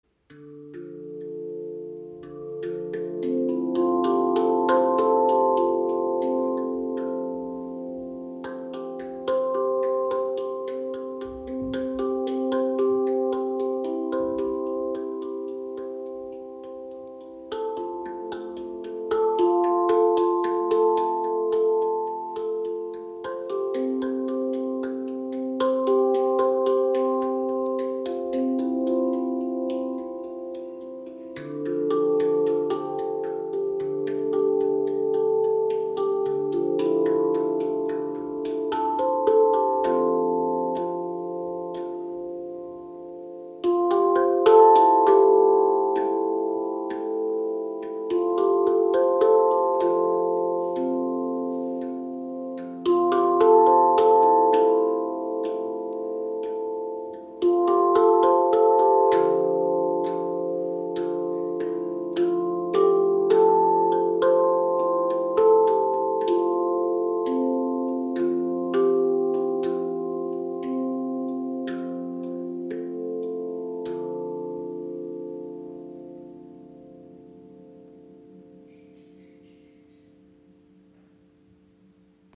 Klangbeispiel